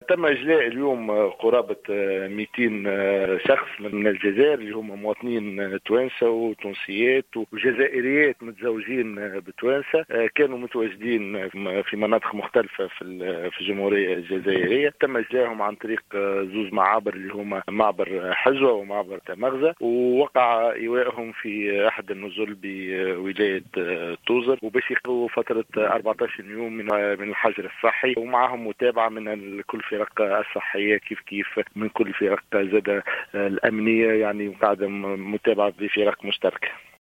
أفاد والي توزر أيمن البجاوي، في تصريح لـ "الجوهرة اف أم" مساء اليوم الاثنين بأنه تم إجلاء اليوم قرابة 200 شخص تونسي وجزائريات متزوّجات من تونسيين كانوا عالقين في الجزائر وتمكنوا من العبور الى تونس عبر معبري حزوة وتمغزة الحدوديان.